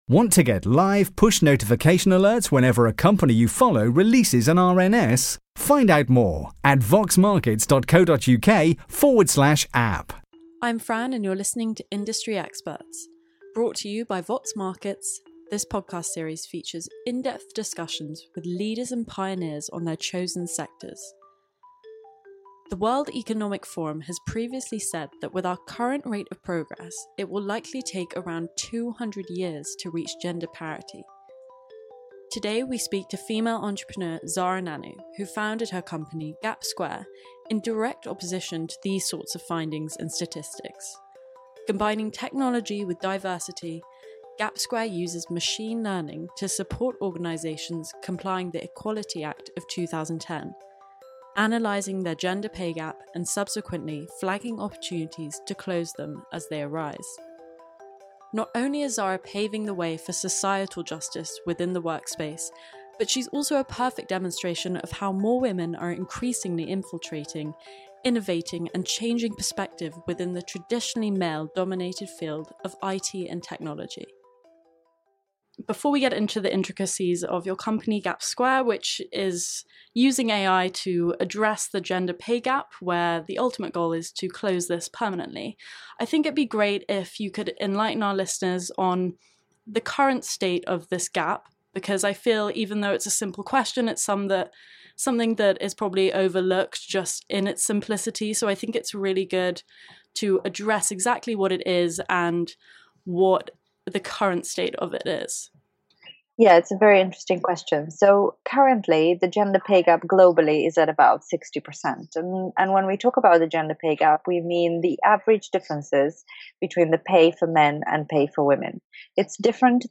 Welcome to Vox Markets Originals. This channel features a range of interviews across four series: Business Lives, Industry Experts, Moving Averages and Spotlight.